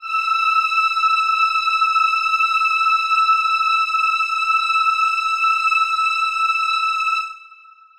Choir Piano (Wav)
E6.wav